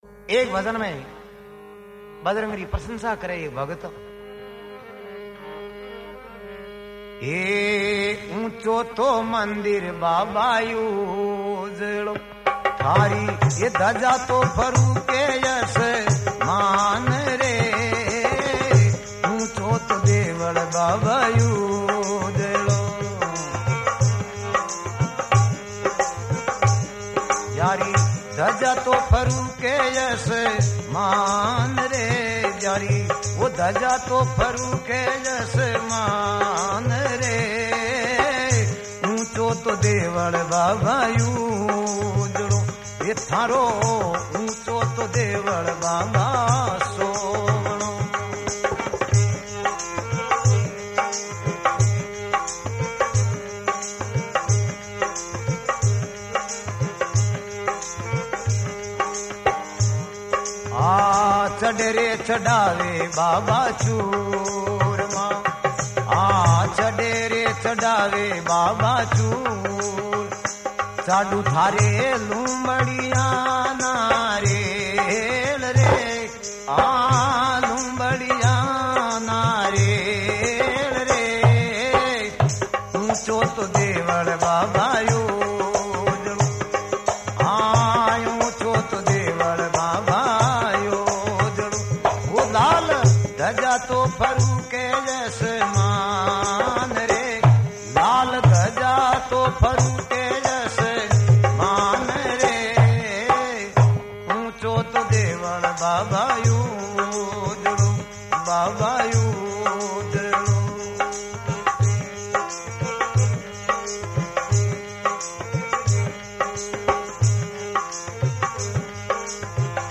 Rajasthani Songs
Hanuman Bhajan